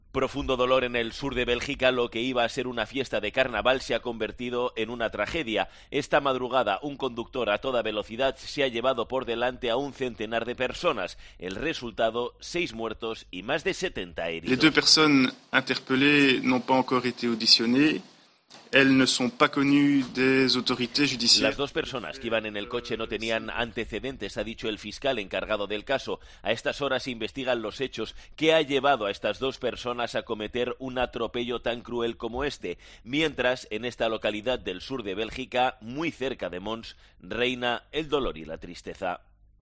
Internacional Tragedia en Bélgica. Crónica